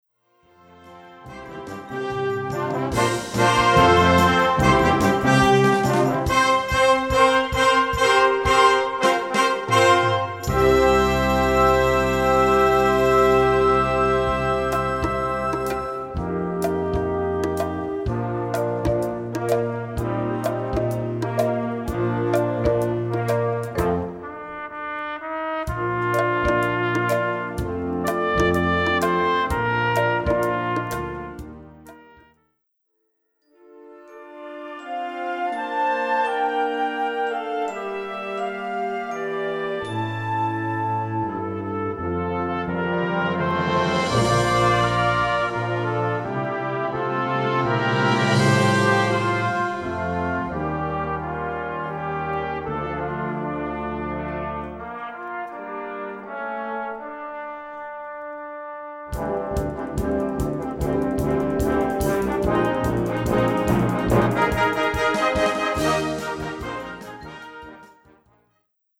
Gattung: Flex Band (5-stimmig)
Besetzung: Blasorchester